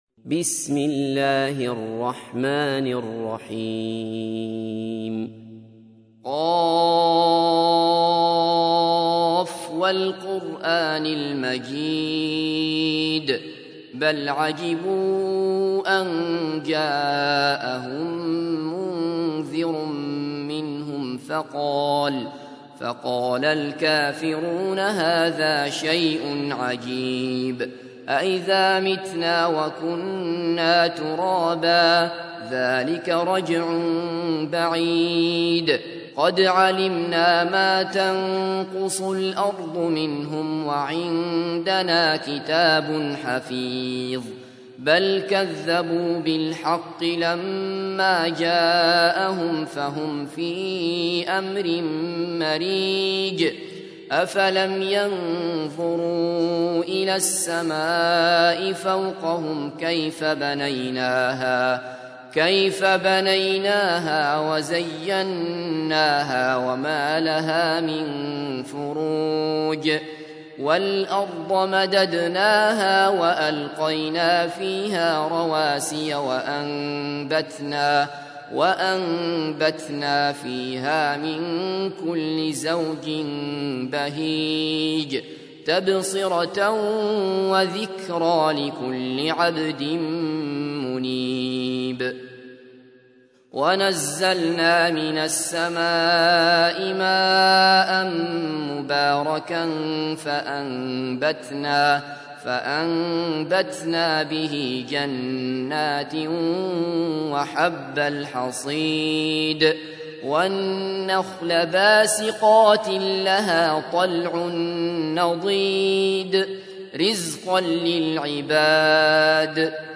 تحميل : 50. سورة ق / القارئ عبد الله بصفر / القرآن الكريم / موقع يا حسين